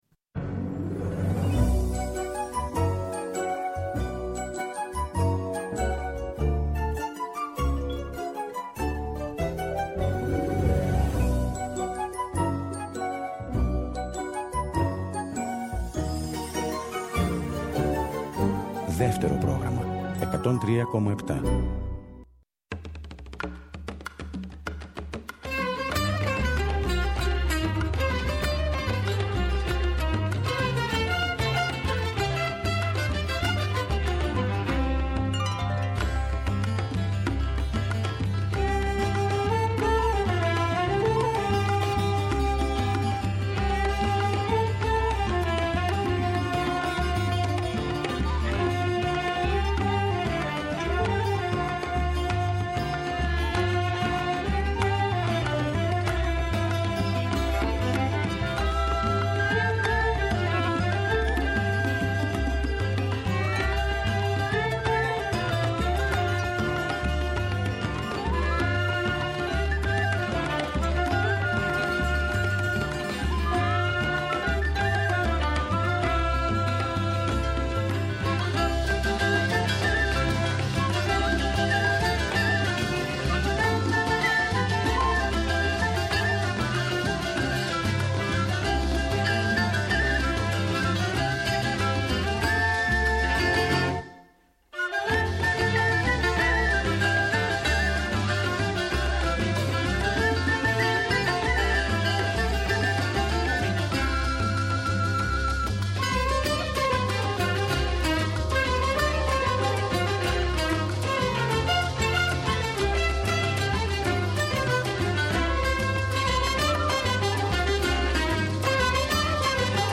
Τα τραγούδια μας σε tempo alegro. Μικρές και μεγάλες ιστορίες που γίνανε τραγούδια και συντροφεύουν τις νυχτερινές μας περιπλανήσεις.